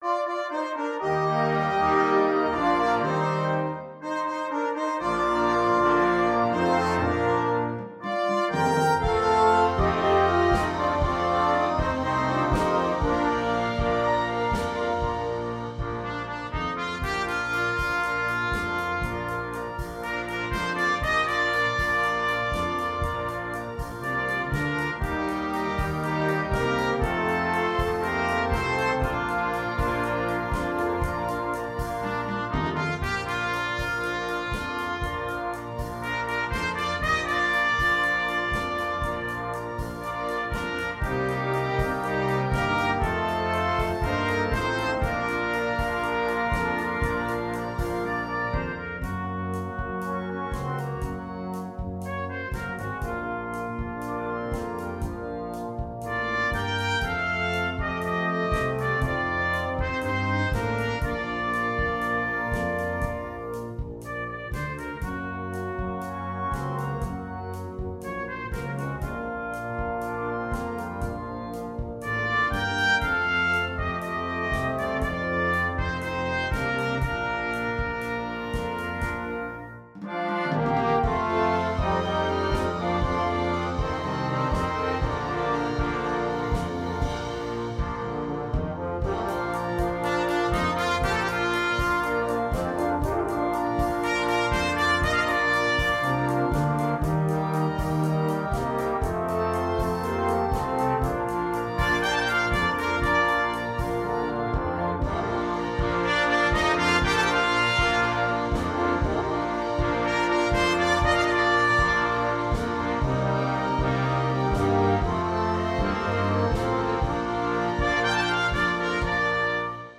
avec instrument soliste
Trompette sib (Solo)
Musique légère